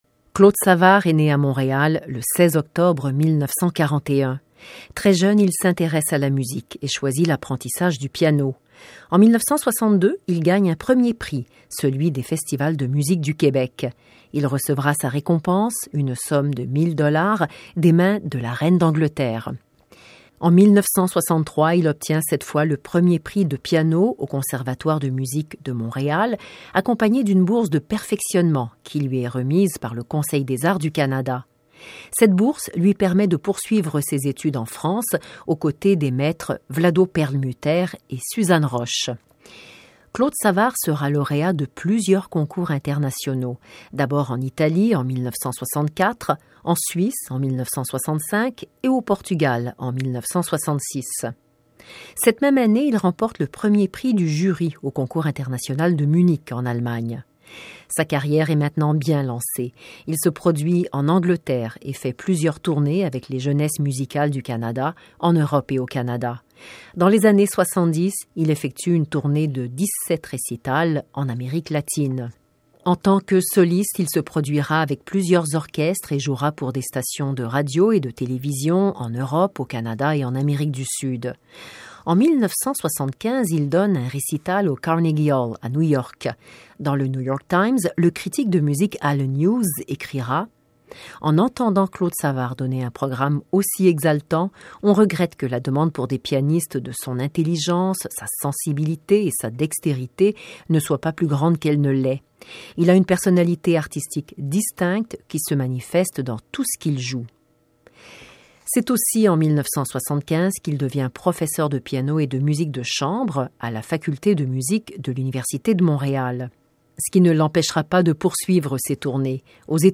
Plusieurs artistes canadiens ont enregistré sur 78 tours d’abord et sur 33 tours ensuite, dans les studios de Radio Canada International.